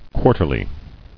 [quar·ter·ly]